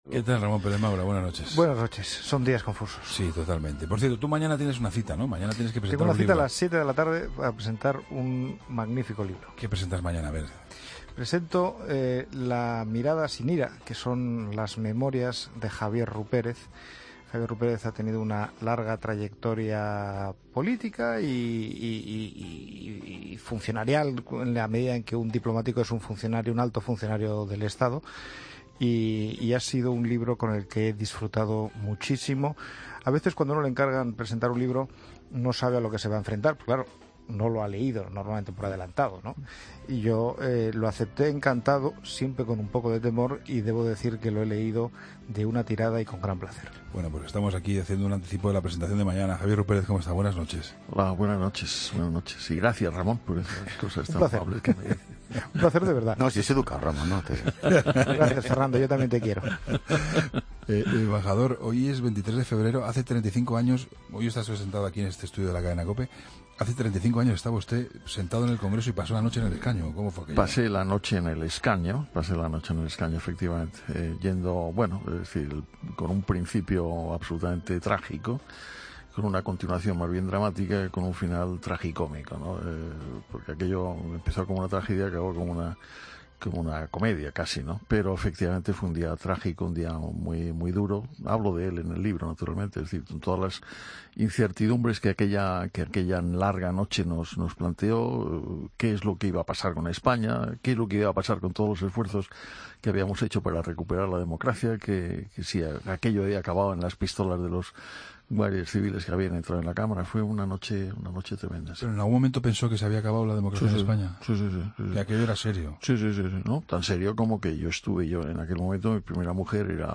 Escucha la entrevista a Javier Rupérez, autor del libro 'La mirada sin ira'